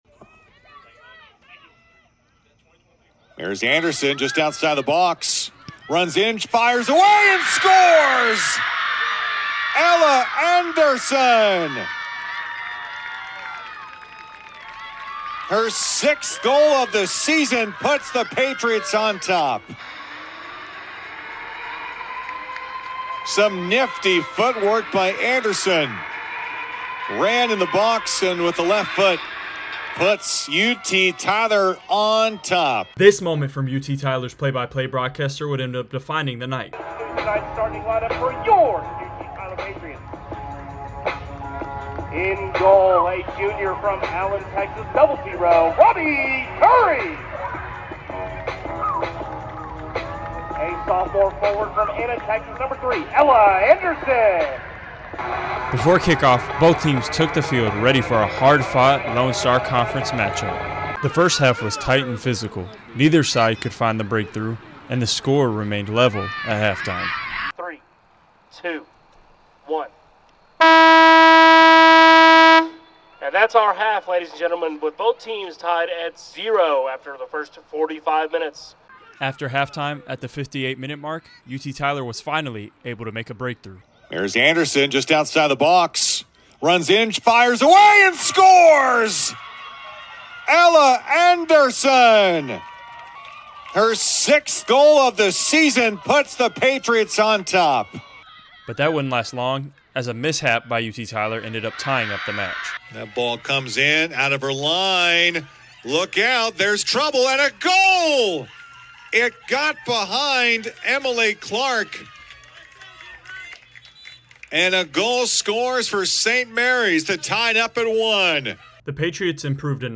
Audio story